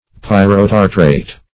Pyrotartrate \Pyr`o*tar"trate\, n.
pyrotartrate.mp3